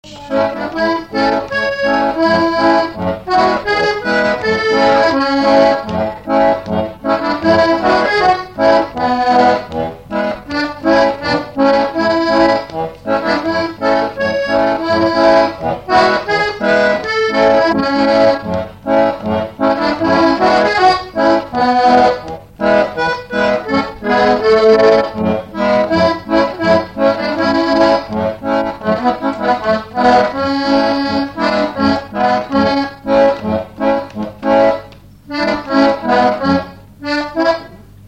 Fox-trot
Instrumental
danse : fox-trot
Pièce musicale inédite